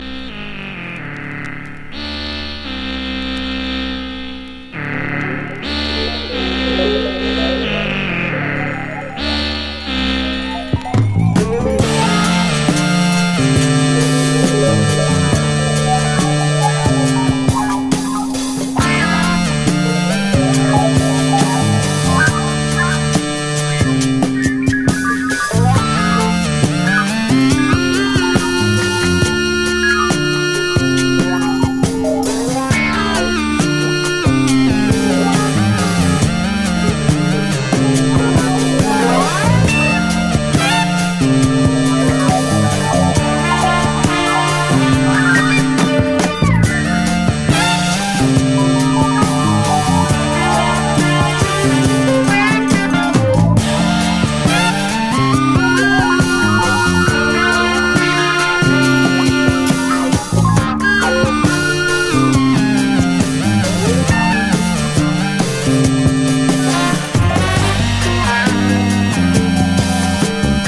ネッチョリ電子ファンク